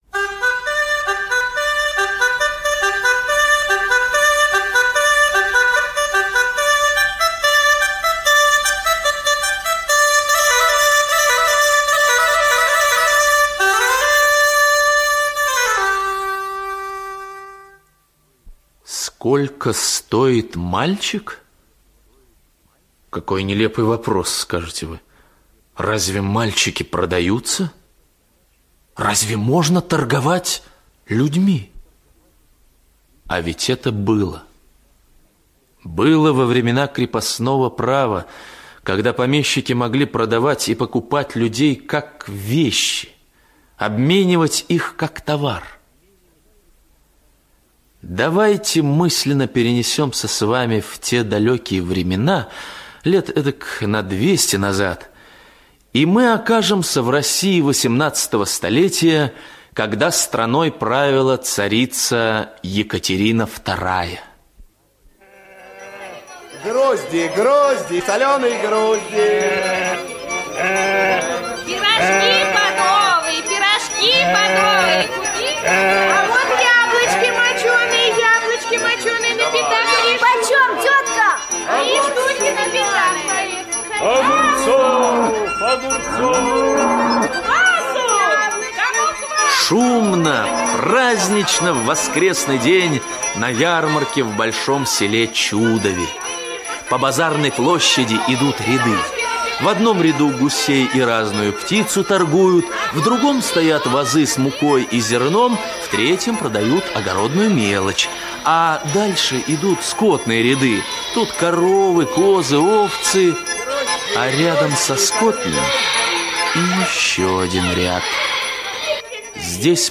История крепостного мальчика — слушать аудиосказку Сергей Алексеев бесплатно онлайн